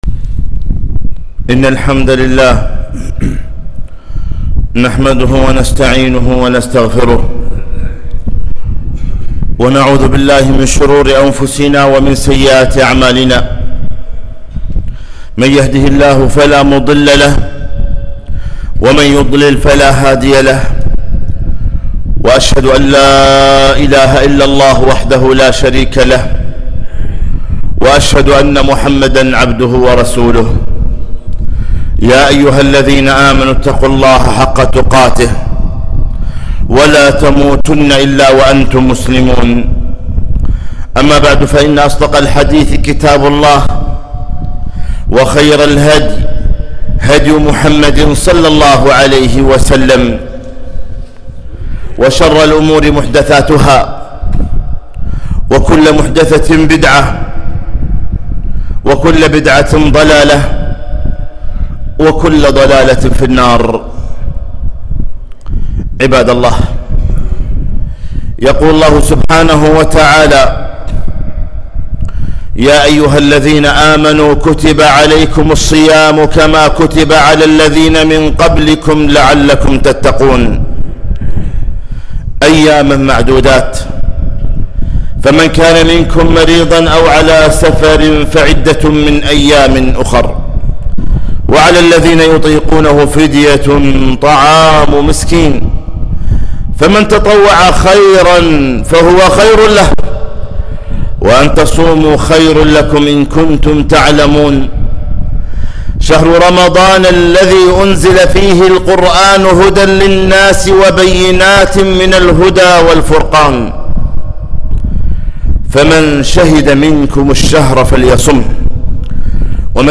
خطبة - مختصر أحكام الصيام